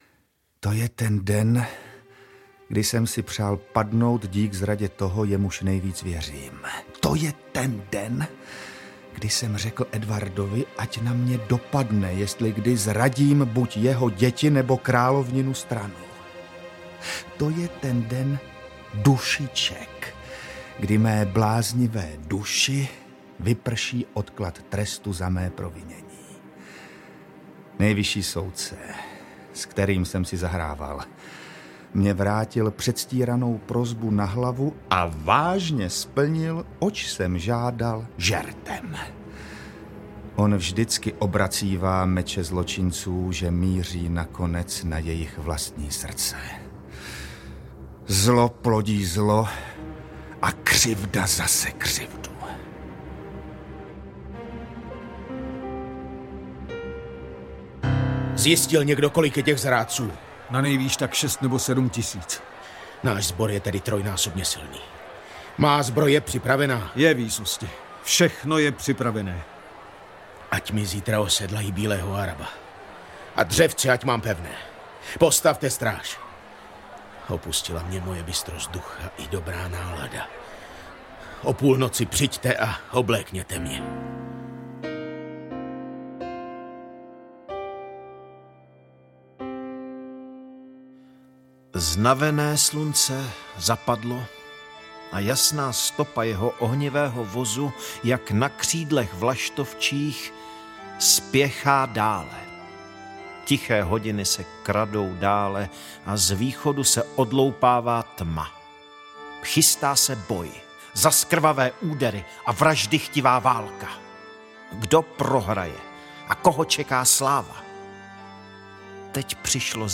Richard III. audiokniha
Historická divadelní hra s padouchem v hlavní roli.
Ukázka z knihy